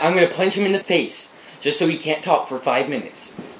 im gonna punch him in the face Meme Sound Effect
im gonna punch him in the face.mp3